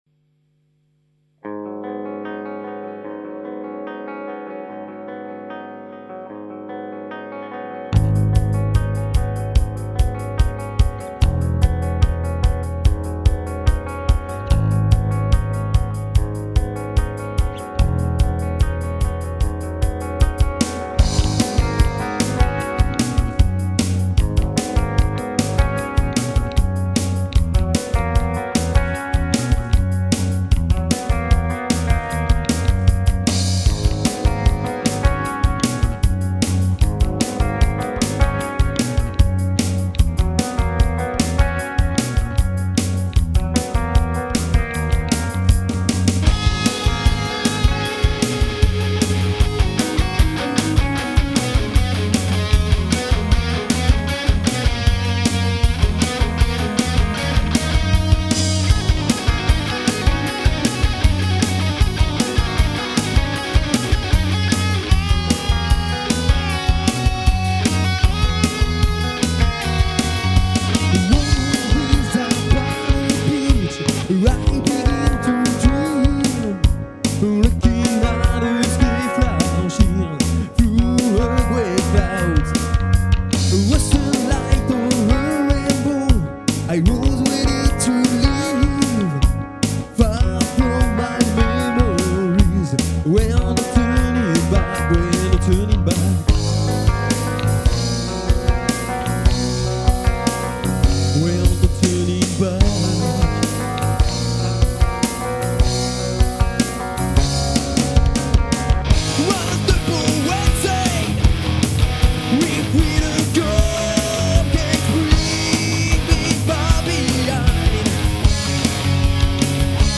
Guitares et choeurs
Chant
Batterie et choeurs
Basse